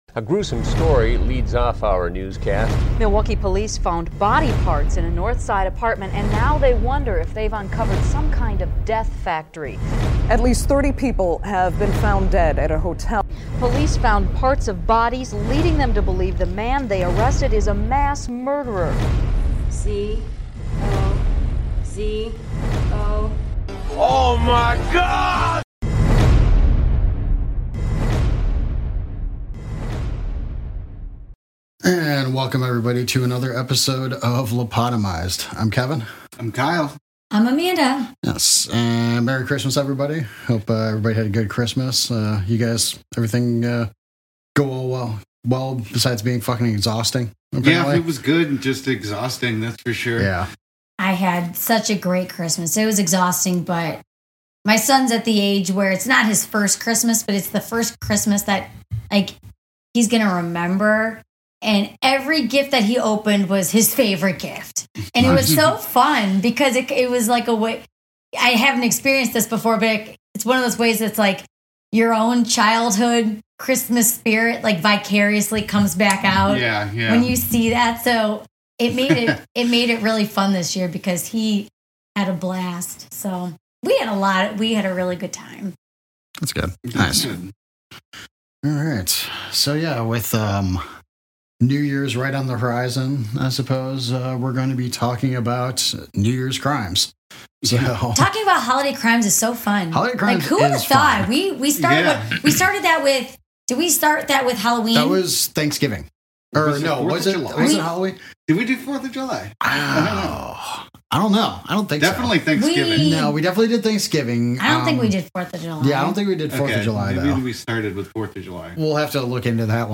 The Trio of Positivity talks about the amazing come-from-behind win against the Packers. 🎙 About Trio of Positivity Podcast Welcome to the Trio of Positivity Podcast, a Chicago Bears podcast that sounds like your group chat, but with more "positivity."